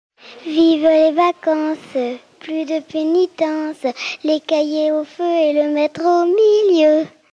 formulette enfantine : amusette
Pièce musicale éditée